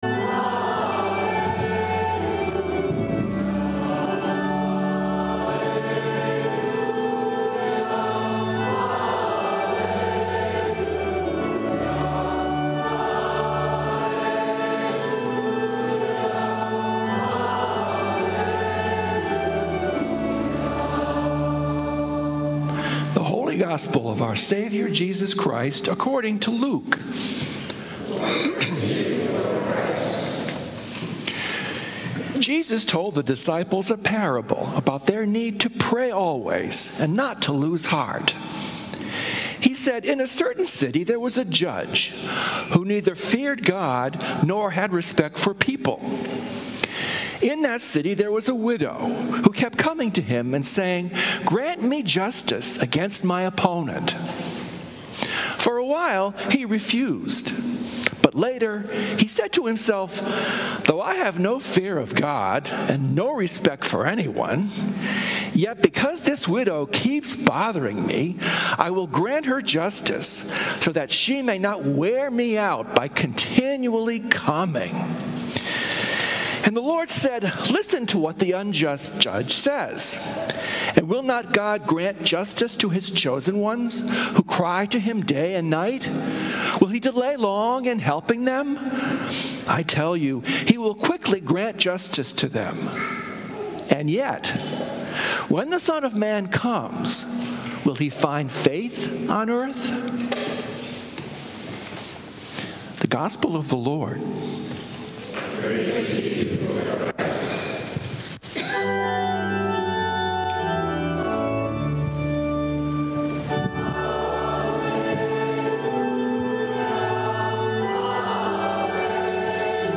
Sunday Sermon
Sermons from St. Columba's in Washington, D.C.